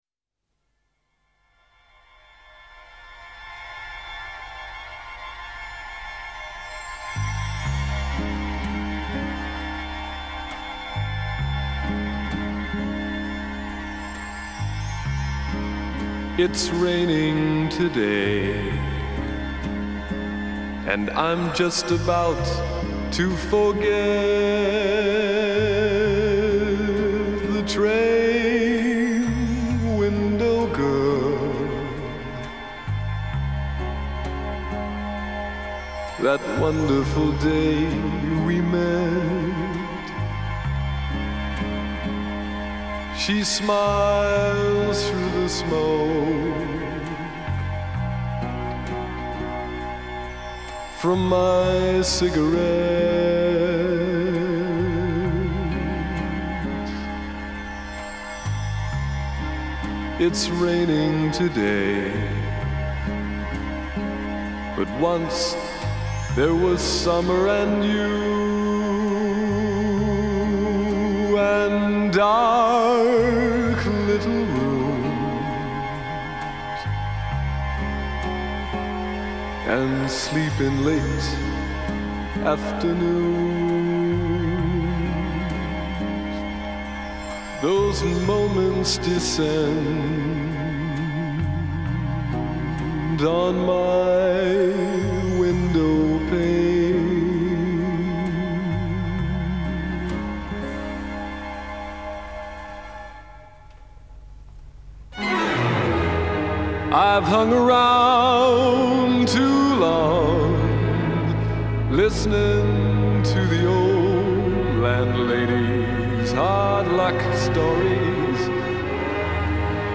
stately and grandiose
searing and soaring string scores